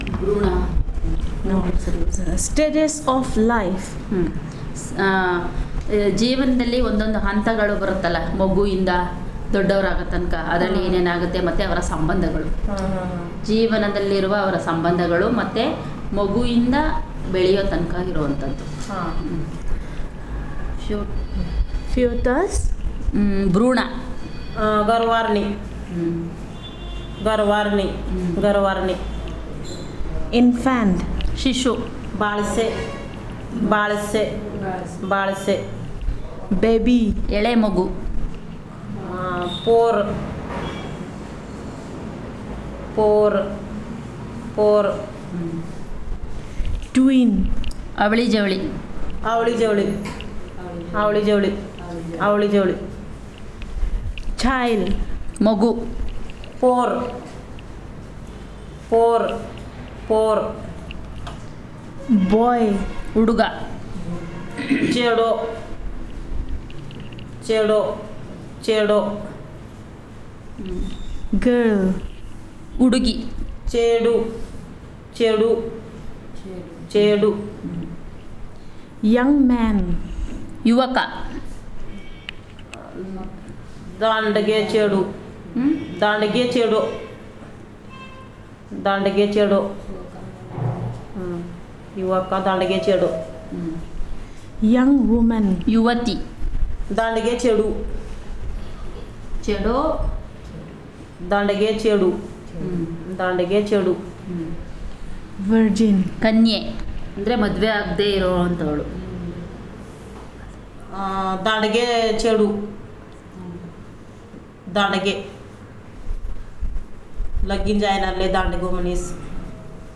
Elicitation of words about Stages of life